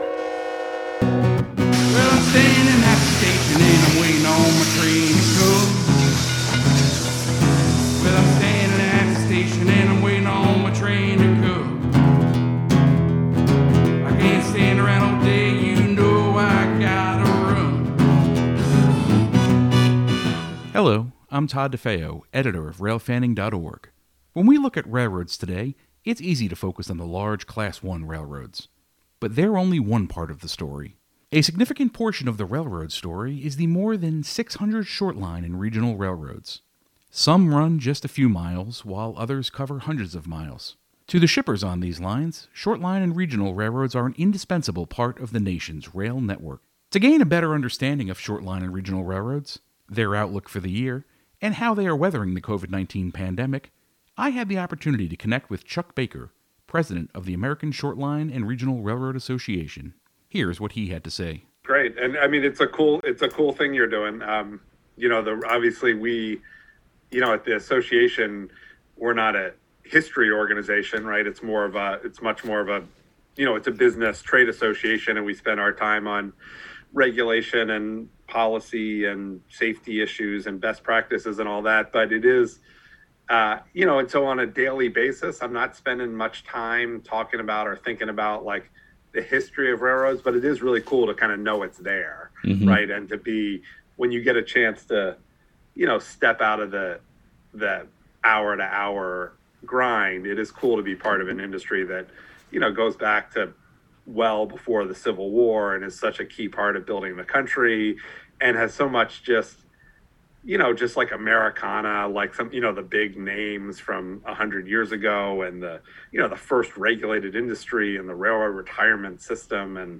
Sound Effects Diesel Horn: Recorded at the Southeastern Railway Museum on Nov. 14, 2020. Steam Train: 1880s Train, recorded Sept. 12, 2020, in Hill City, South Dakota. Show Notes This conversation has been edited slightly to remove some brief moments of silence and snippets of dialogue for the sake of clarity.